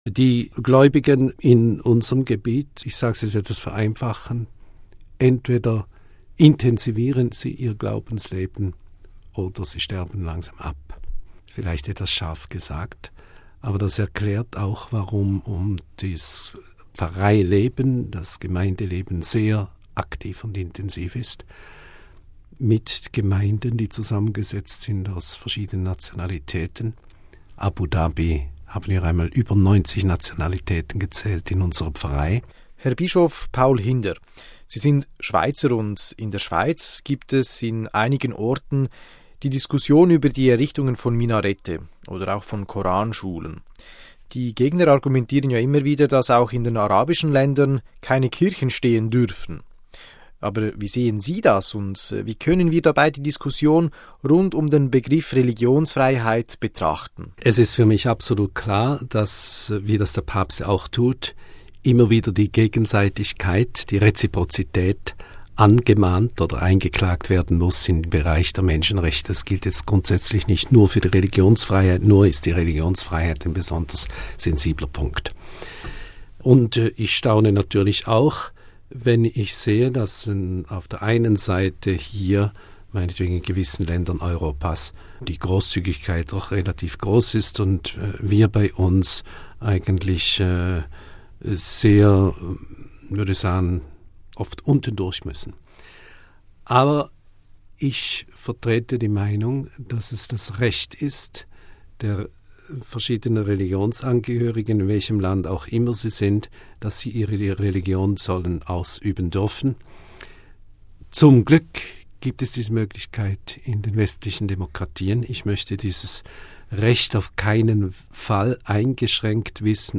Aber es kam ganz anders: Vor zwei Jahren wurde der heute 64-jährige deutschsprachige Schweizer zum Bischof und Apostolischen Vikar von Arabien ernannt.